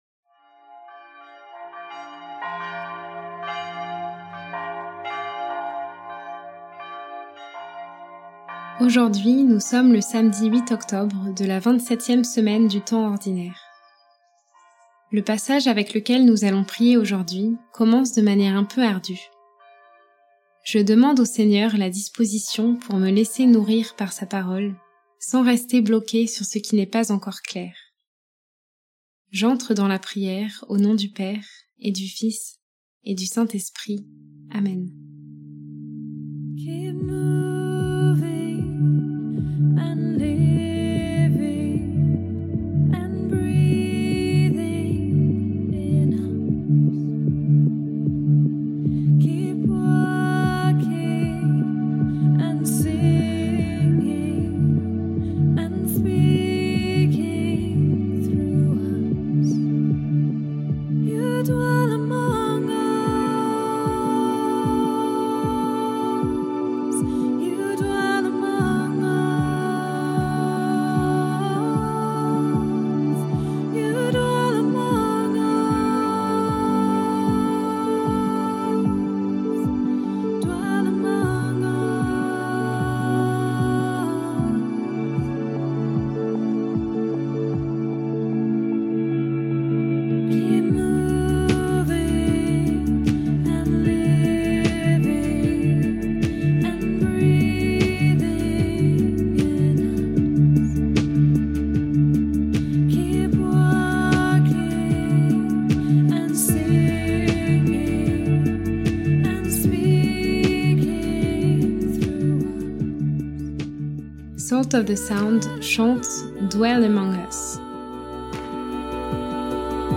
Prière audio avec l'évangile du jour - Prie en Chemin